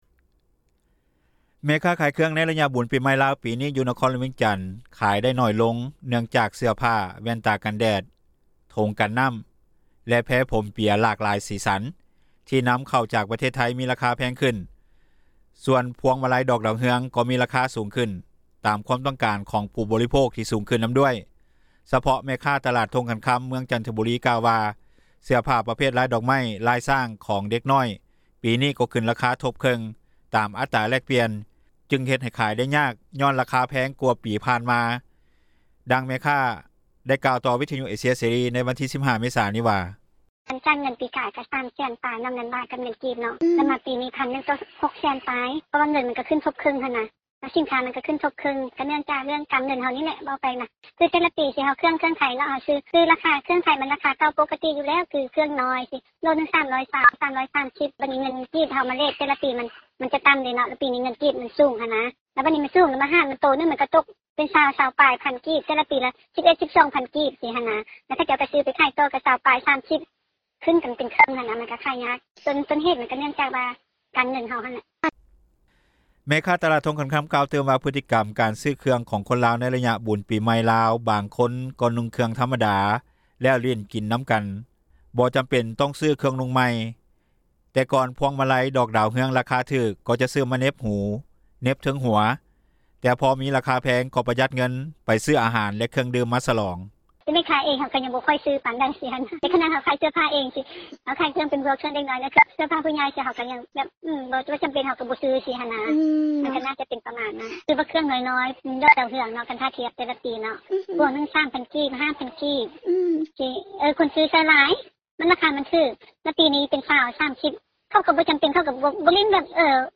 ດັ່ງແມ່ຄ້າກ່າວຕໍ່ ວິທຍຸເອເຊັຽເສຣີ ໃນວັນທີ 15 ເມສາ ນີ້ວ່າ:
ດັ່ງນາງກ່າວວ່າ: